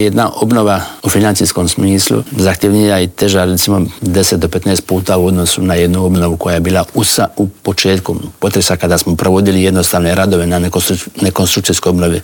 Ministar graditeljstva i potpredsjednik Vlade Branko Bačić u Intervjuu tjedna Media servisa istaknuo je da će kompletna obnova biti završena do 2030. godine: "Uvijek može brže i bolje, ali zadovoljan sam dinamikom obnove."